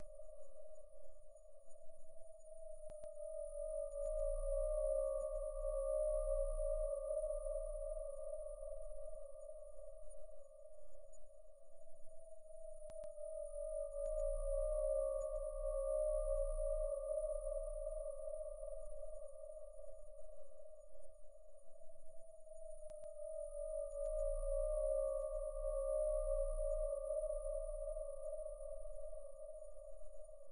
科学幻想的声音 " 空间波浪1
Tag: 机械 电子 机械 未来 航天器 外星人 空间 科幻 噪音